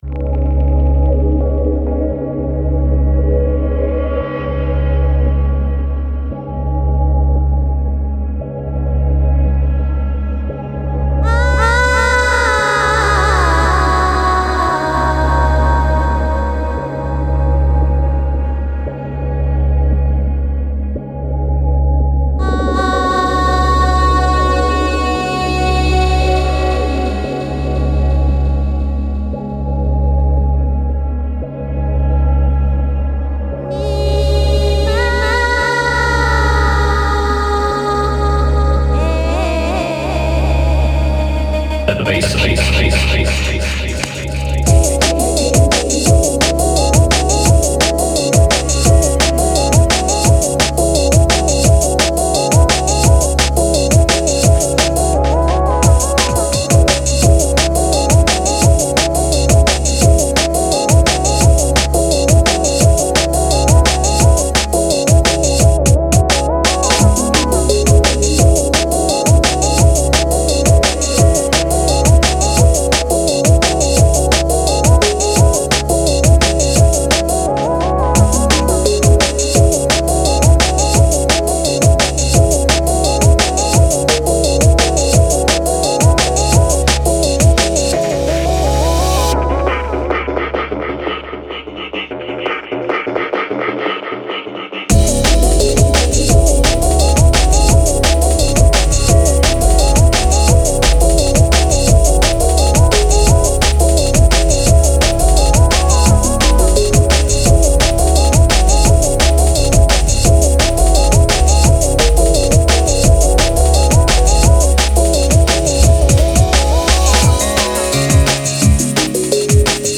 Genre: Electro.